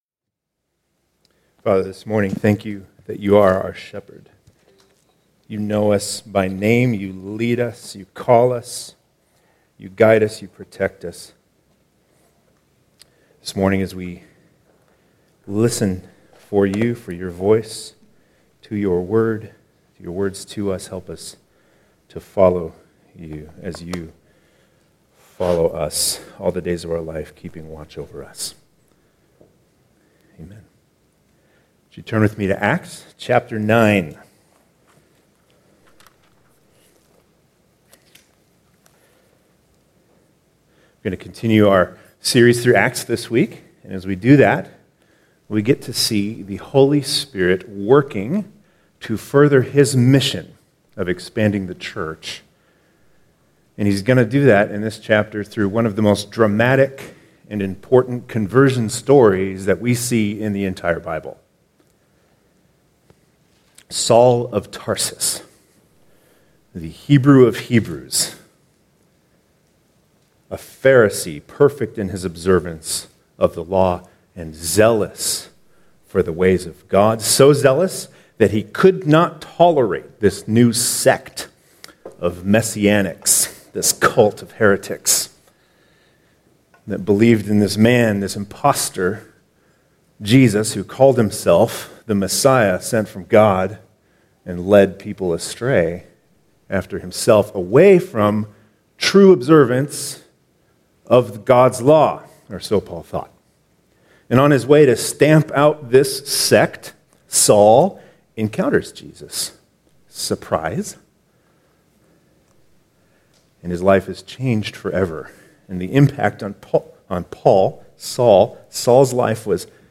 Download sermon manuscript